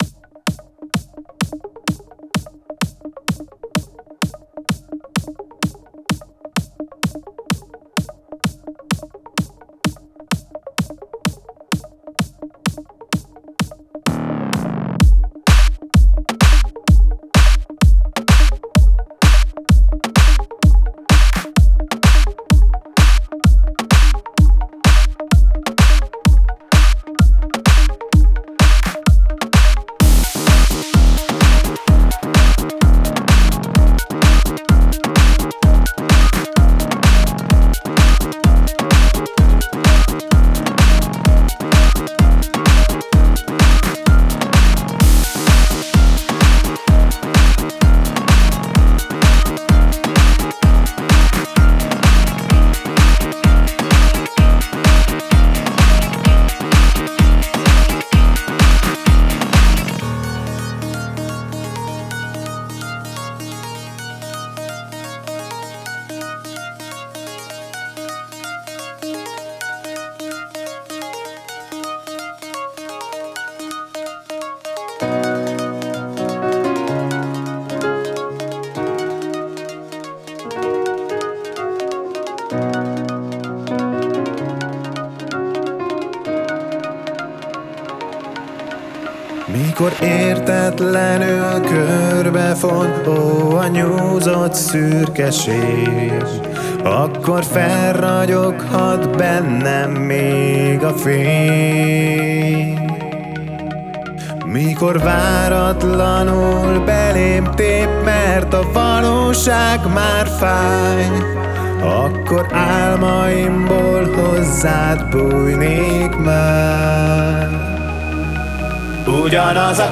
egy pörgős electro-pop felvétel